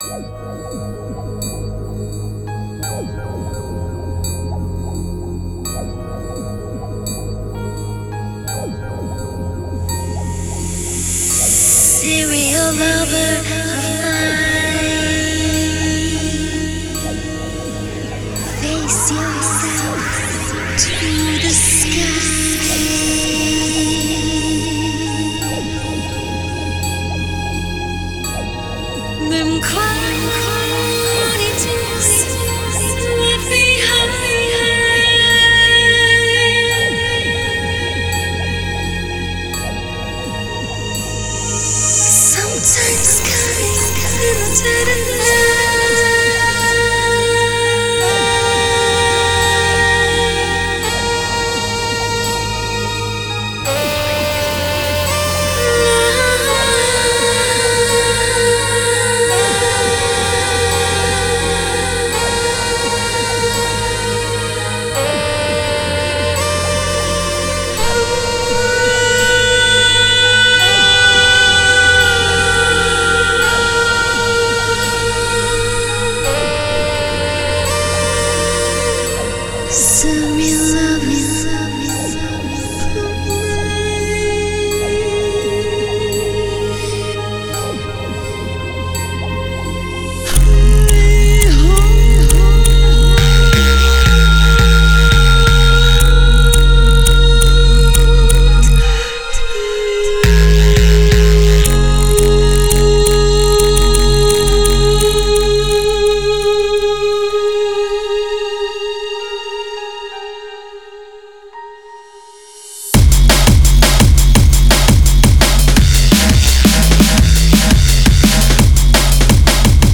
Style: Drum and Bass, Dubstep